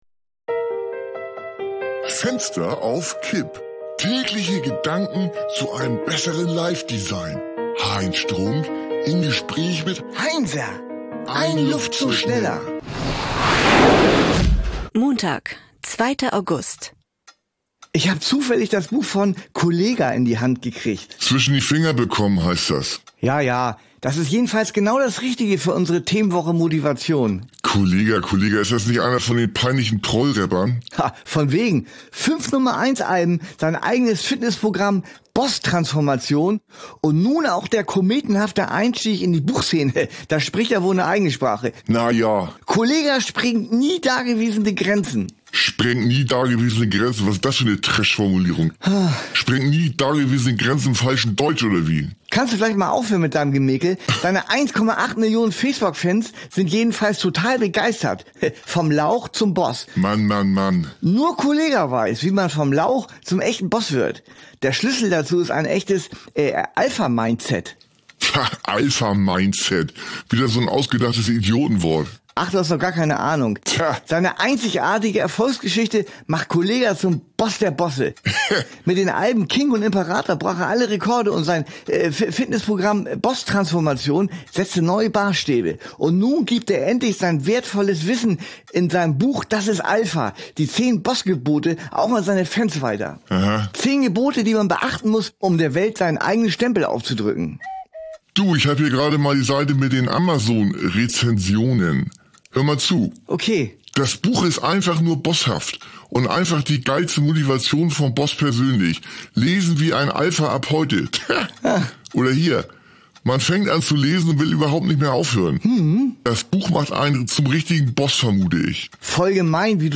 eine Audio-Sitcom von Studio Bummens